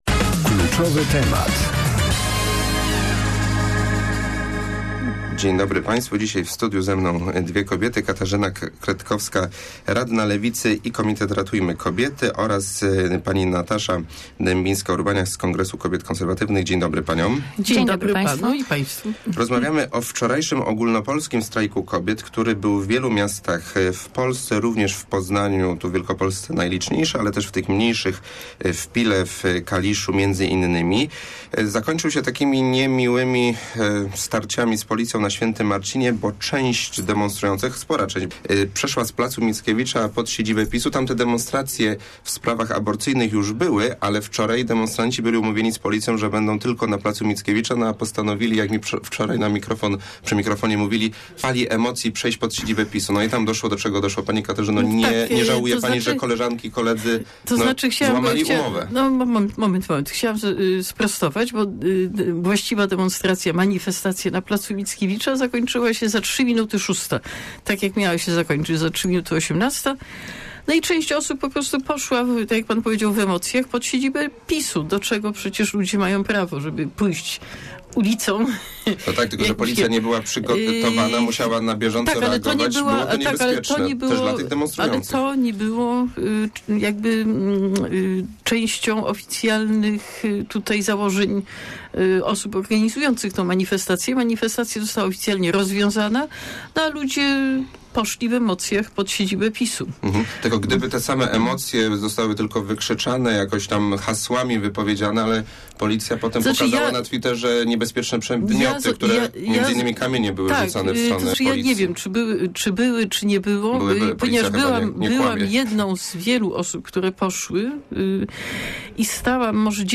98j9mij5uthido1_rozmowa_strajk_kobiet.mp3